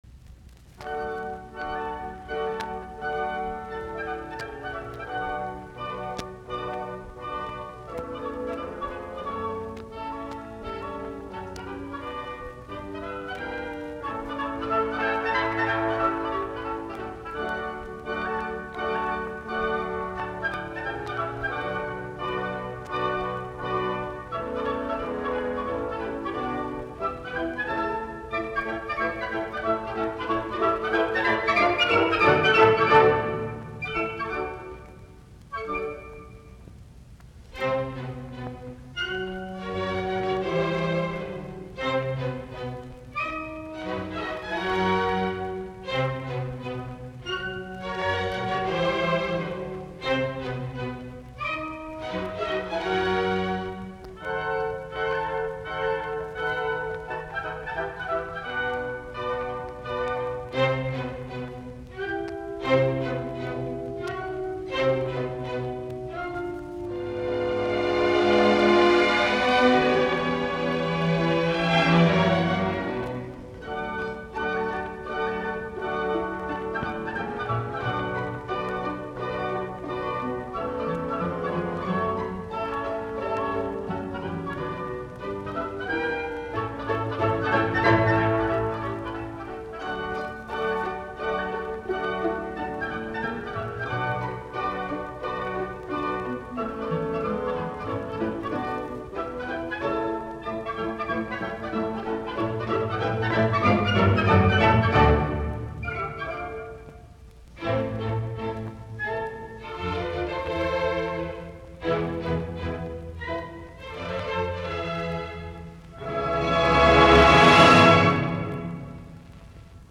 musiikkiäänite
Soitinnus: Ork.